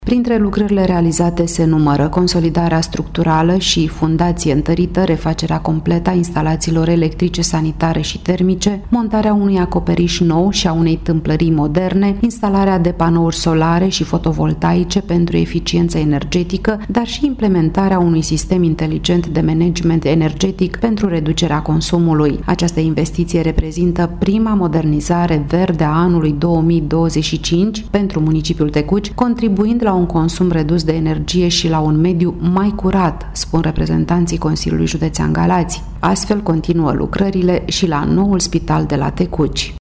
Corespondenta noastră